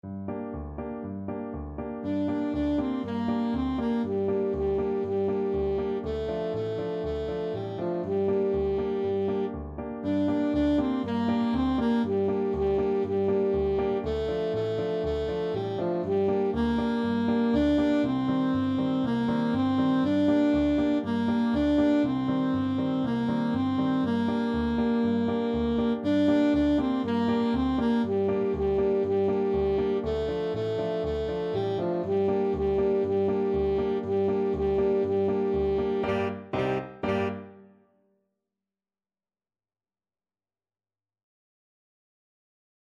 Alto Saxophone version
Allegro vivo (View more music marked Allegro)
4/4 (View more 4/4 Music)
World (View more World Saxophone Music)